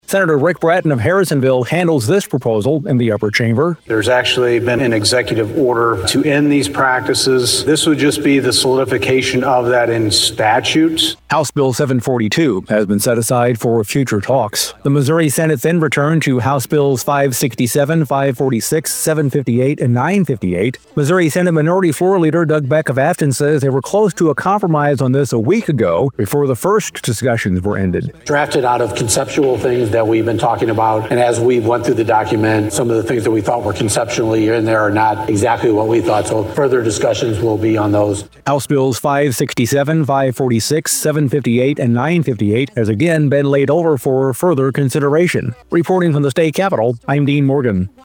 (KFMO)